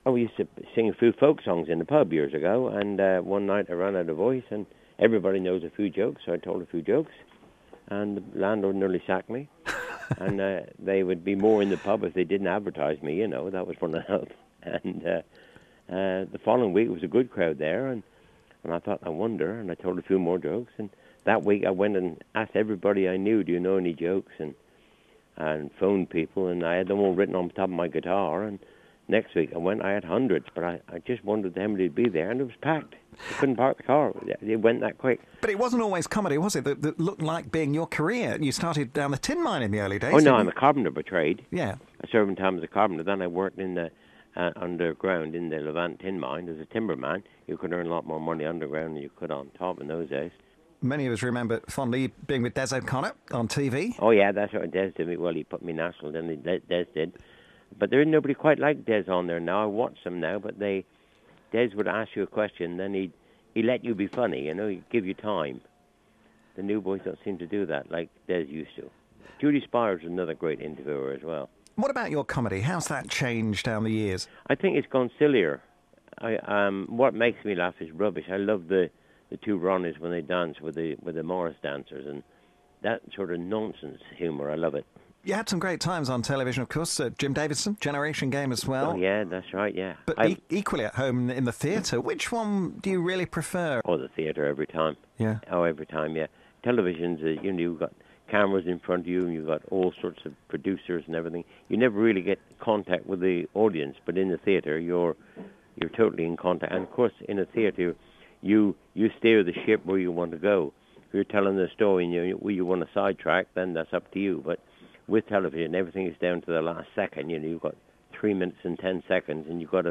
The Cornish comedian in conversation during his nationwide tour.